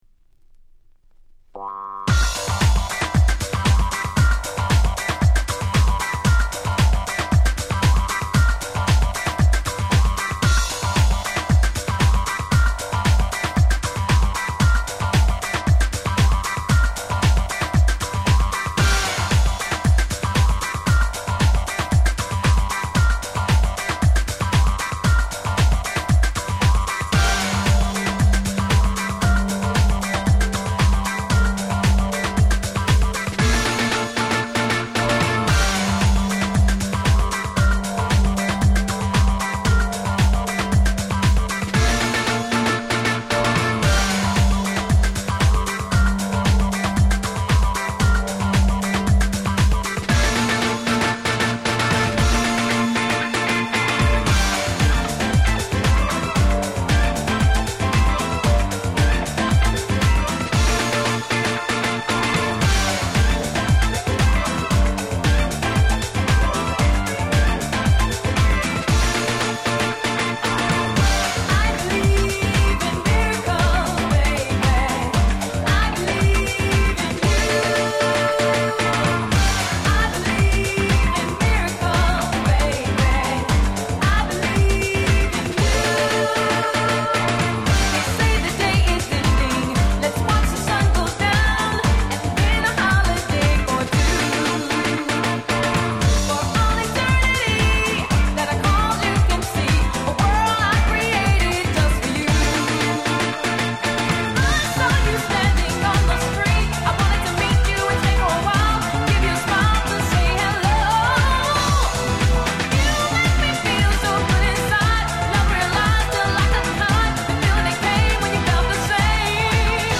04' Nice Japanese House/R&B !!